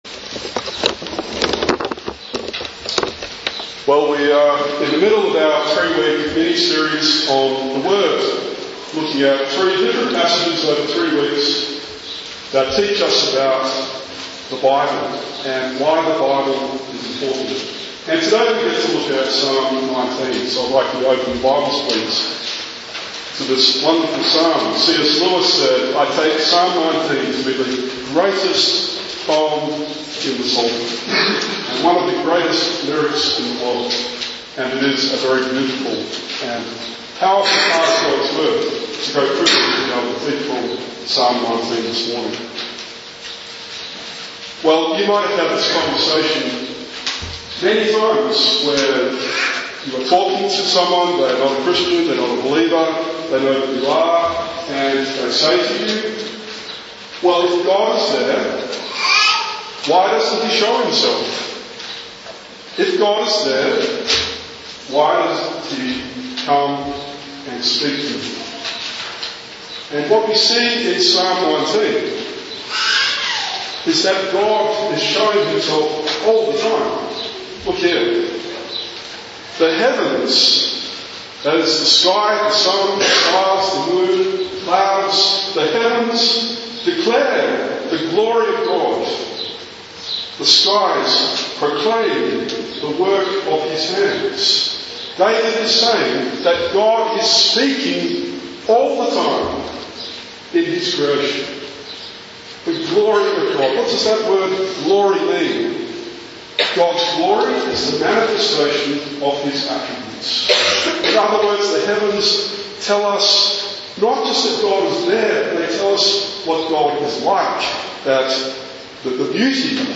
Today is the second of a miniseries of three sermons on the Bible. Psalm 19 teaches us that God reveals himself to all people all the time in His creation, and reveals Himself in a saving way to sinful humanity in His written Word.